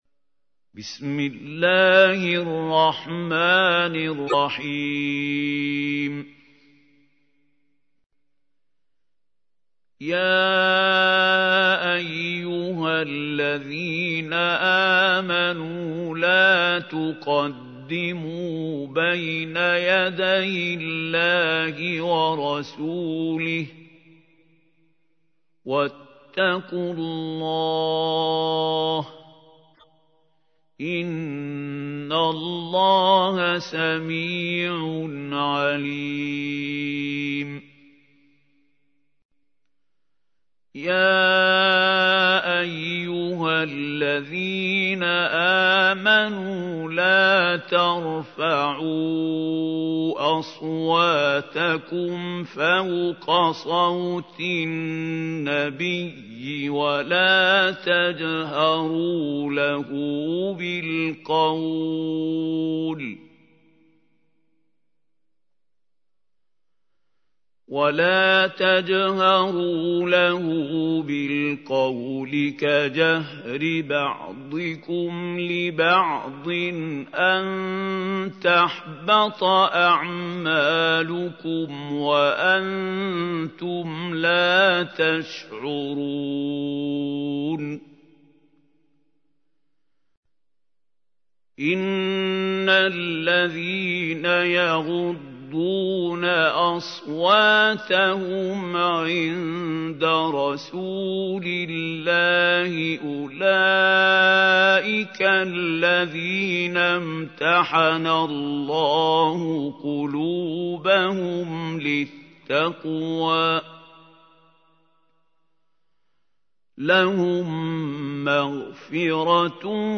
تحميل : 49. سورة الحجرات / القارئ محمود خليل الحصري / القرآن الكريم / موقع يا حسين